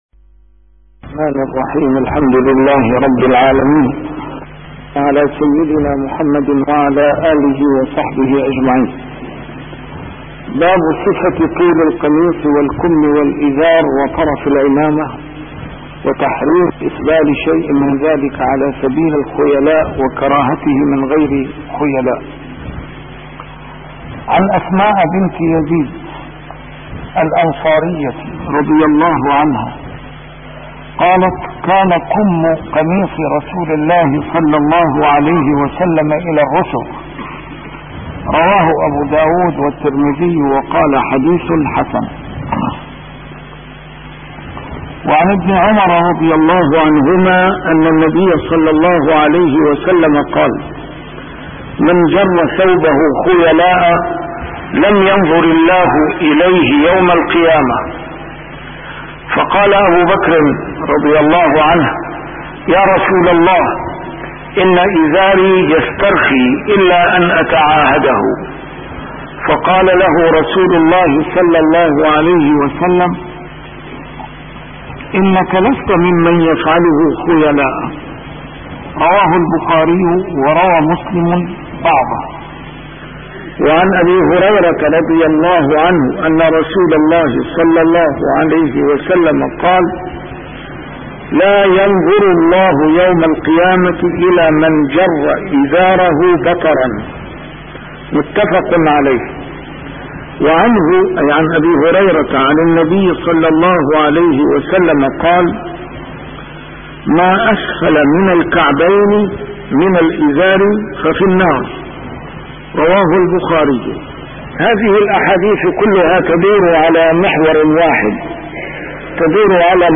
A MARTYR SCHOLAR: IMAM MUHAMMAD SAEED RAMADAN AL-BOUTI - الدروس العلمية - شرح كتاب رياض الصالحين - 659- شرح رياض الصالحين: صفة طول القميص